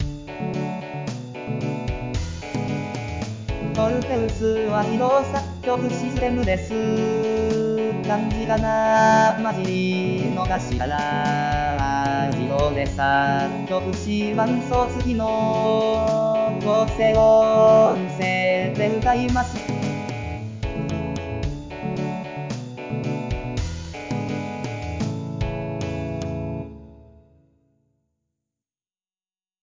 自動で作曲し、伴奏つきの
合成音声で歌います。